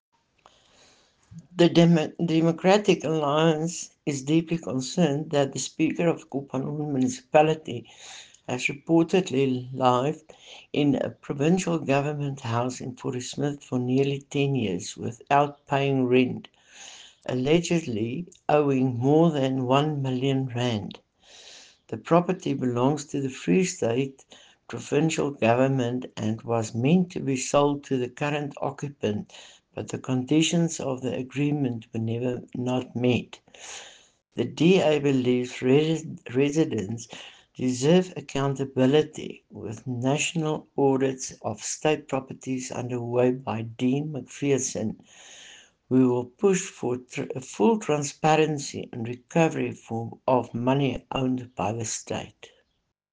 Afrikaans soundbites by Cllr Estelle Noordman and